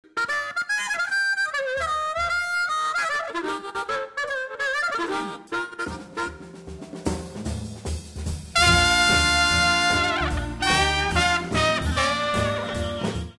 Recording enhanced by The FIReverb Suite™